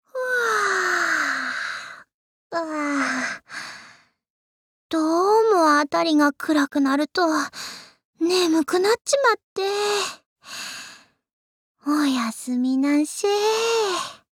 贡献 ） 协议：Copyright，其他分类： 分类:雪之美人语音 您不可以覆盖此文件。